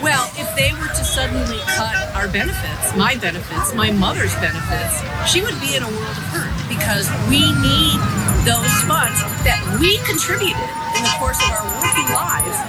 KALAMAZOO, MI (WKZO AM/FM) – Hundreds of people protested outside the Social Security Office in Kalamazoo on Saturday, demanding the federal government not make changes to the program.